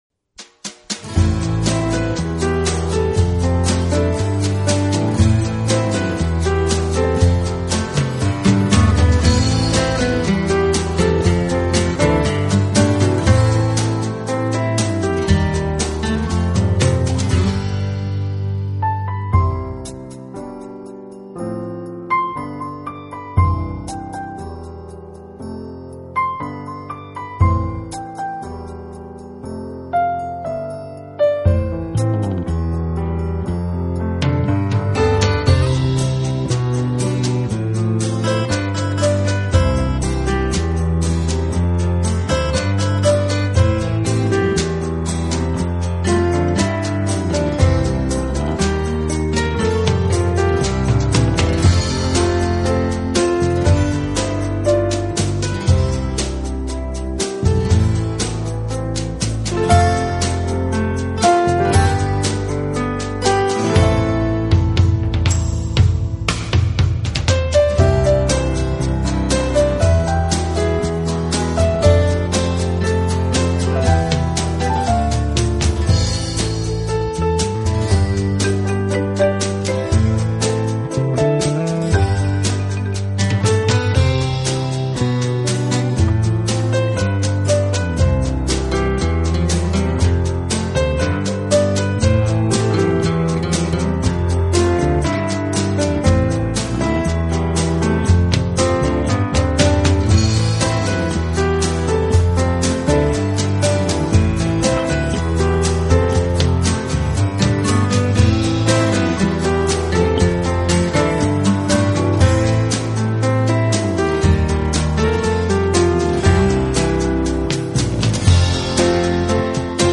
版本：钢琴
专辑以优美的旋律描述自然美景和浪漫心境，钢琴音色温暖， 演